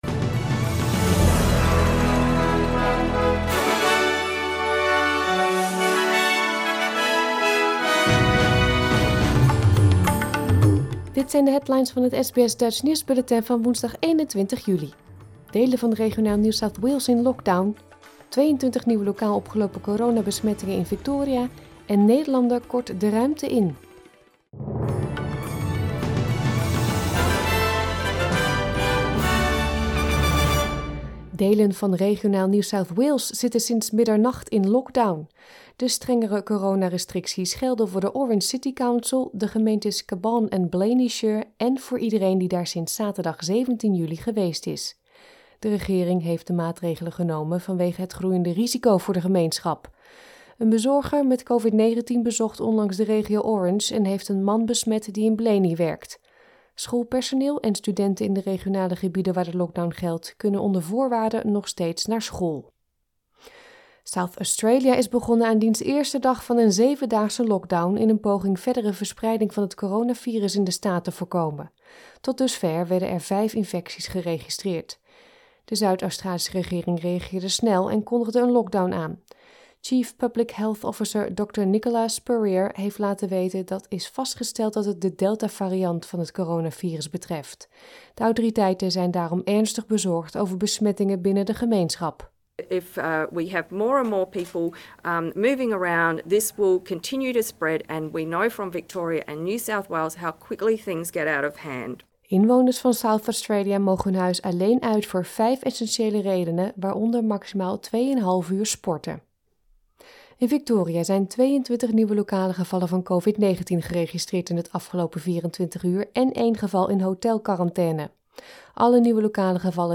Nederlands/Australisch SBS Dutch nieuwsbulletin van woensdag 21 juli 2021